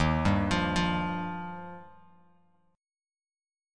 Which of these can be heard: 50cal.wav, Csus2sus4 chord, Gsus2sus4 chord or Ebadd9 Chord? Ebadd9 Chord